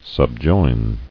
[sub·join]